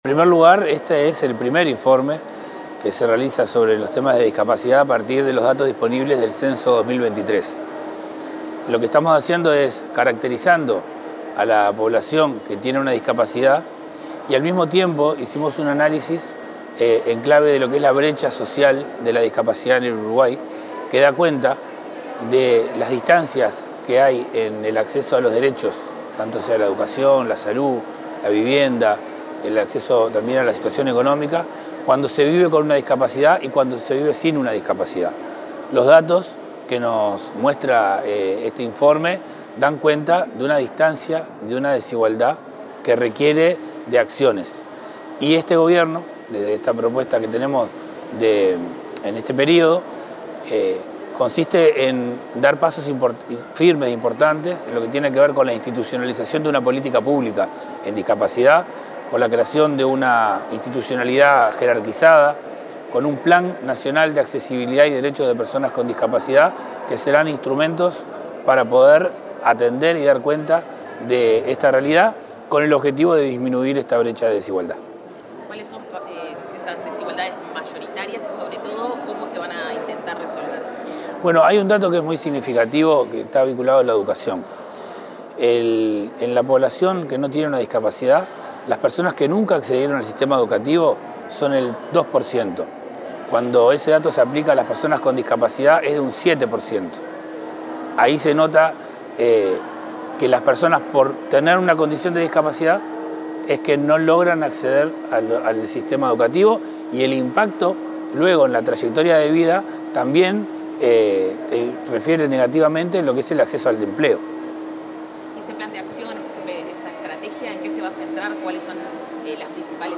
Declaraciones del director del Área de Discapacidad, Federico Lezama
Declaraciones del director del Área de Discapacidad, Federico Lezama 20/10/2025 Compartir Facebook X Copiar enlace WhatsApp LinkedIn Durante la presentación de un informe sobre el acceso a derechos a personas con discapacidad, el director del área específica del Ministerio de Desarrollo Social, Federico Lezama, repasó algunos datos del documento y las políticas que prevé desplegar esta administración en el quinquenio.